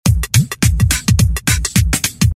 描述：made with the reaktor lime lite ensemble.
标签： drum drumloop drummachine loop snare